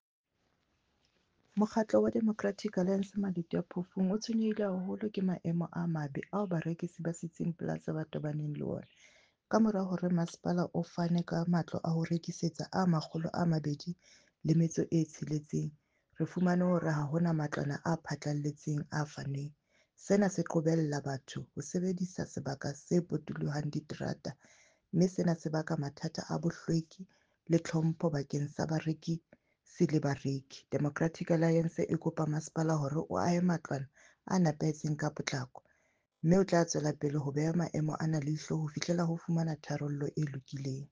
Sesotho soundbite by Cllr Ana Motaung.